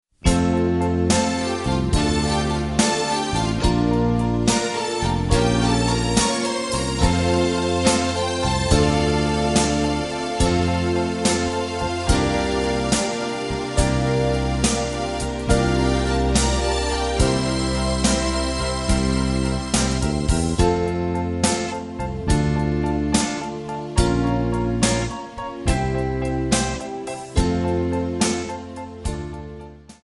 MPEG 1 Layer 3 (Stereo)
Backing track Karaoke
Pop, Oldies, 1950s